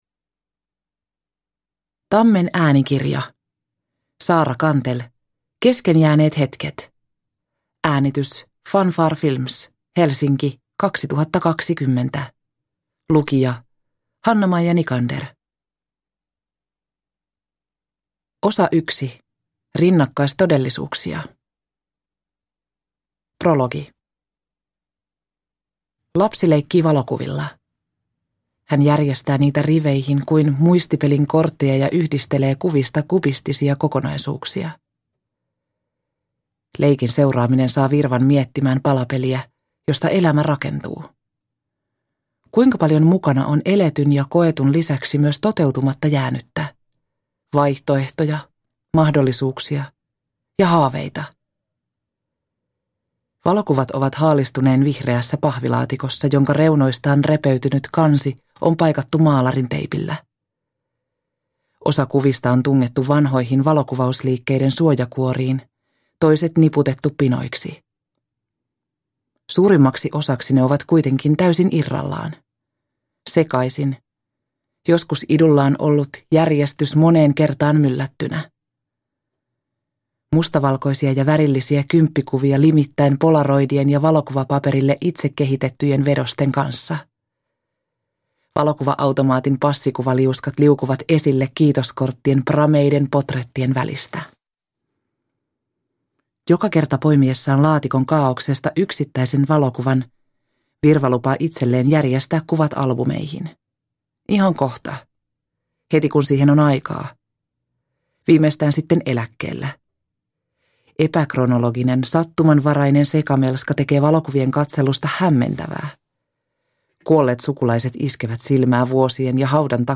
Kesken jääneet hetket (ljudbok) av Saara Cantell